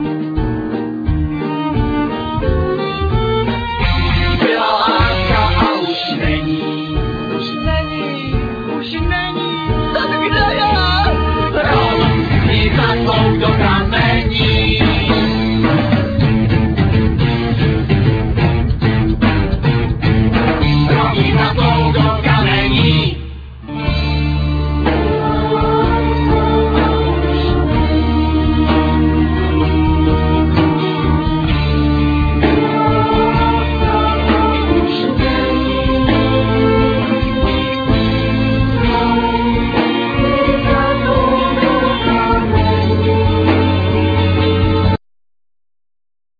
Vocal,Violin,Double bass,Percussion
Guitar,Vocal
Keyborard,Piano,Vocal
Drums,Vocal,Sampler